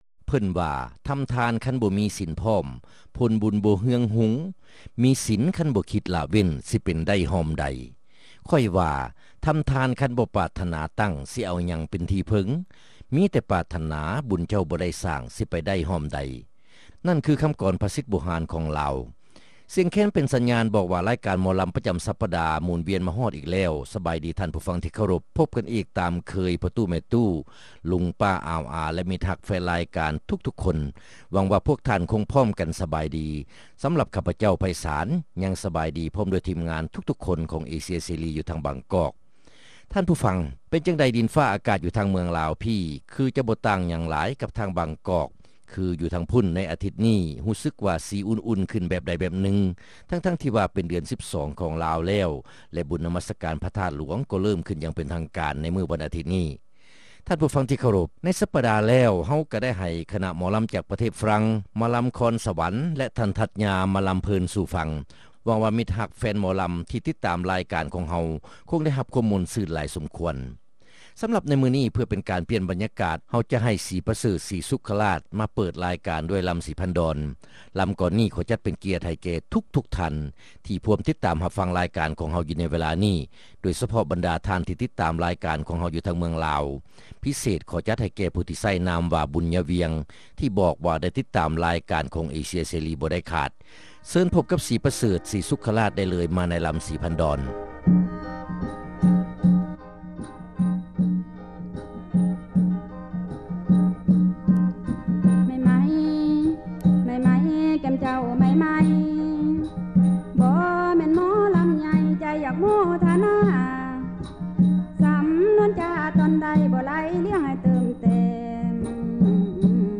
ຣາຍການໜໍລຳ ປະຈຳສັປະດາ ວັນທີ 11 ເດືອນ ພືສະຈິກາ ປີ 2005